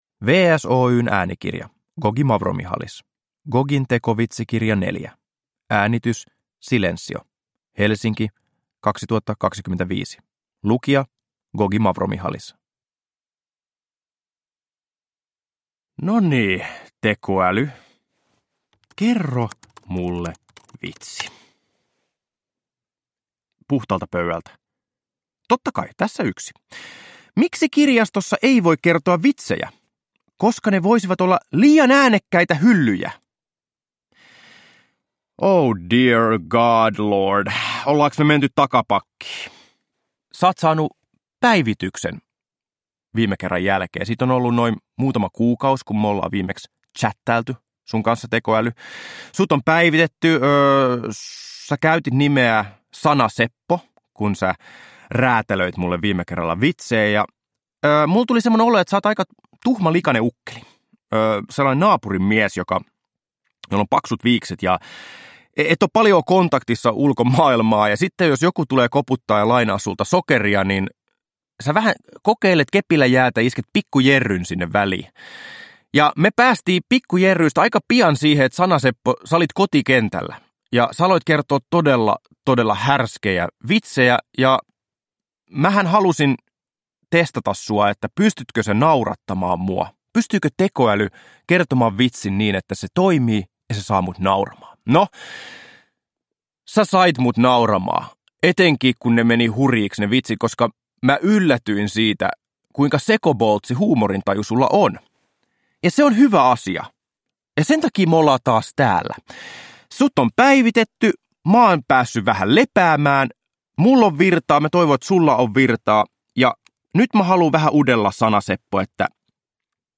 Gogi Mavromichalis istuu äänitysstudioon selvittämään, saako tekoäly hänet nauramaan.
Kaikki Gogin tekovitsikirjan vitsit ovat syntyneet livenä tekoälyn kanssa.
Uppläsare: Gogi Mavromichalis